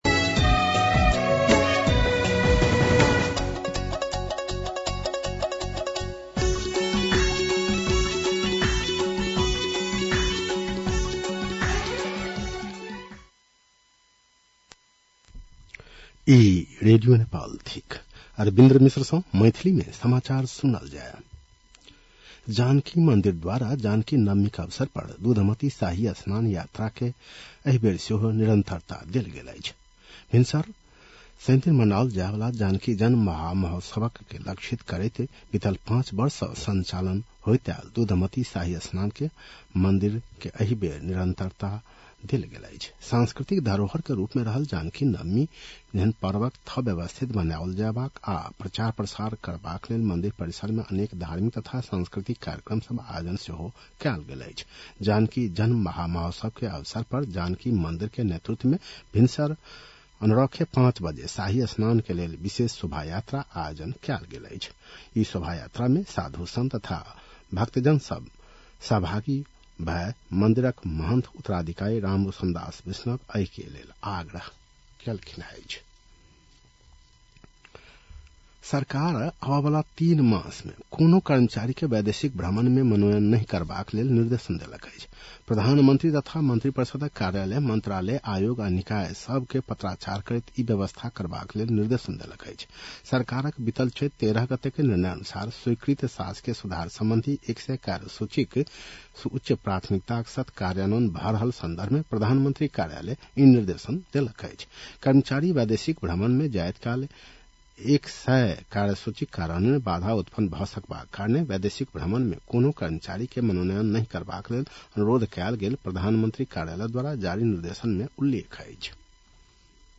मैथिली भाषामा समाचार : ११ वैशाख , २०८३
6-pm-maithali-news.mp3